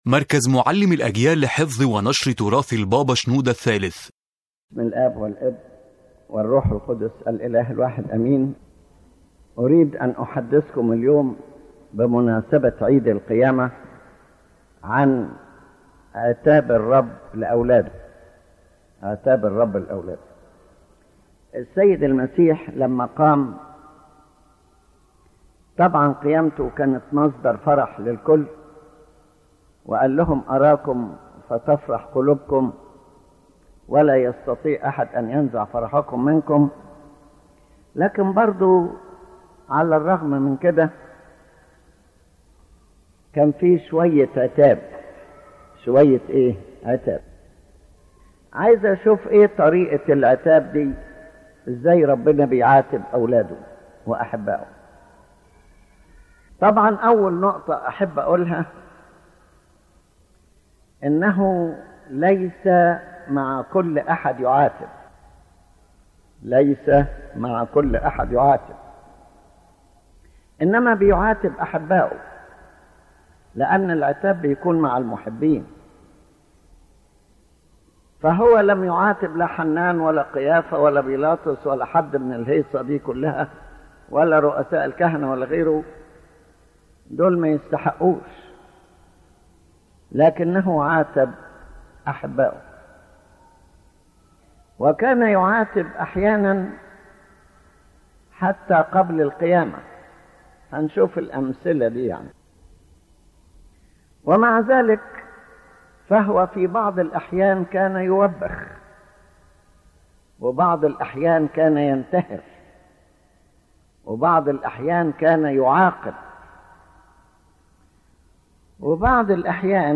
This lecture explains that God’s reproof is not an expression of anger or harshness, but rather a sign of divine love directed toward His children whom He loves and desires their salvation. Divine reproof comes in a gentle and wise manner aiming to correct a person and return him to the right path without wounding or humiliating him.